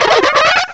cry_not_buizel.aif